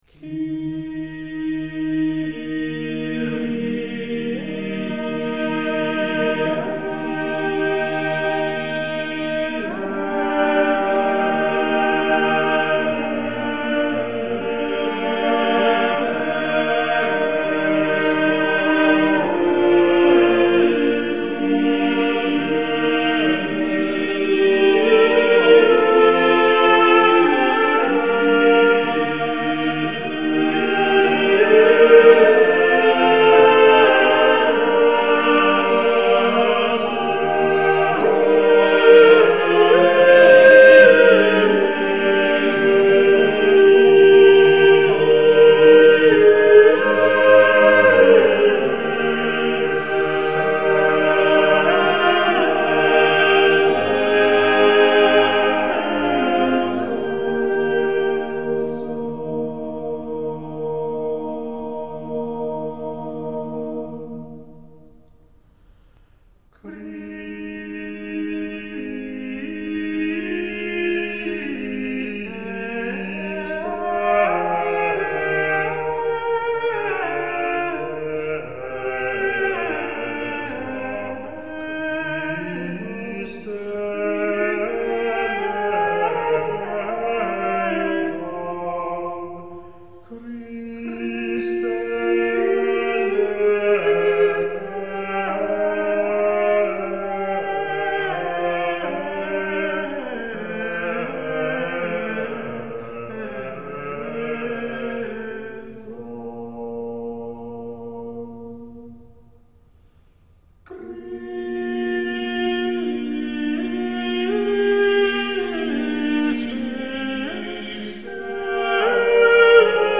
Mensuration canon